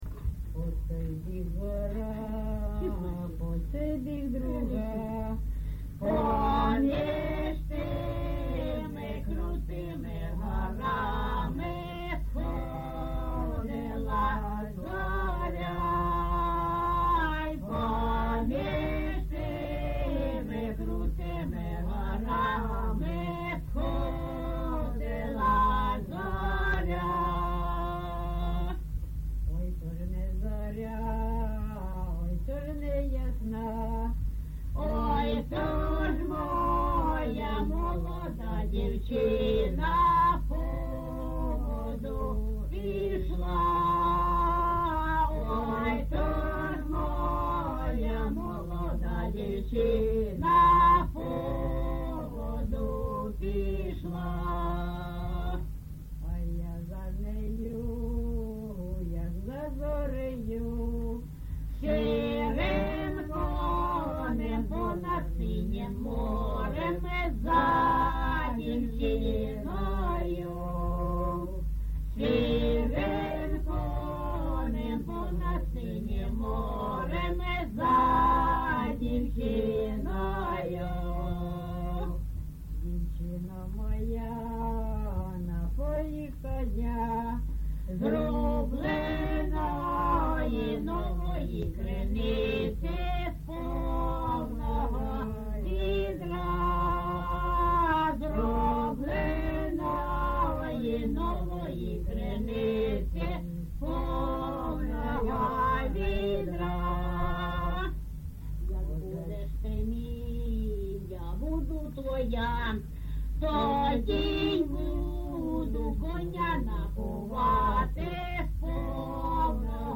ЖанрПісні з особистого та родинного життя
Місце записус. Званівка, Бахмутський район, Донецька обл., Україна, Слобожанщина